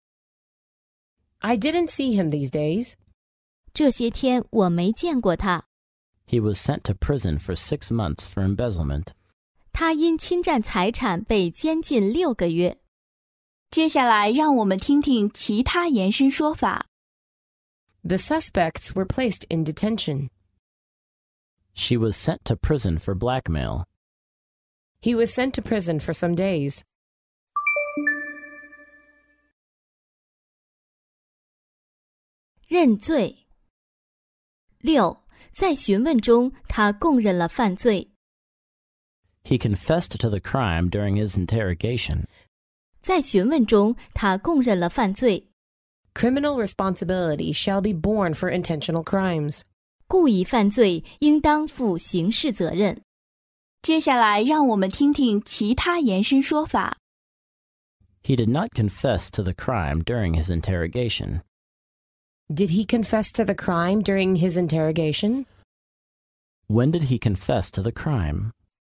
在线英语听力室法律英语就该这么说 第63期:他因侵占财产被监禁6个月的听力文件下载,《法律英语就该这么说》栏目收录各种特定情境中的常用法律英语。真人发音的朗读版帮助网友熟读熟记，在工作中举一反三，游刃有余。